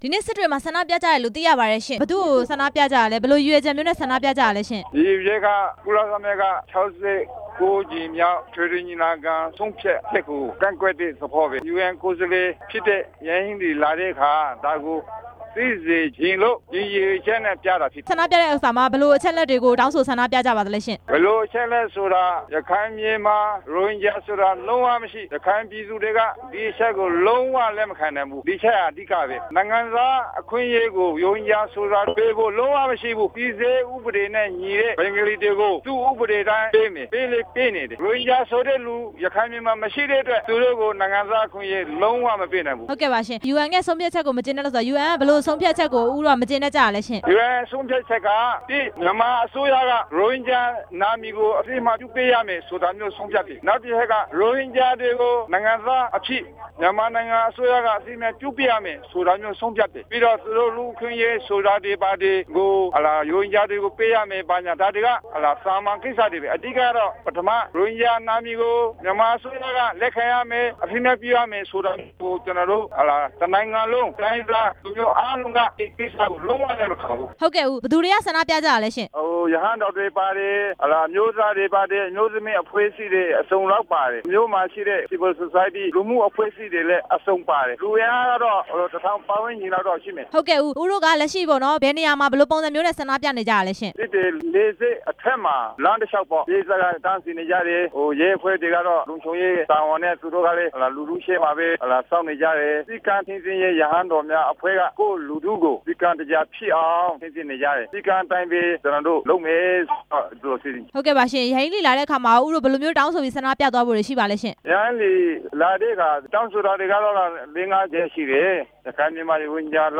မေးမြန်းချက်အပြည့်အစုံ